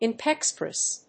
• / ìnɪksprésɪv(米国英語)
inexpressive.mp3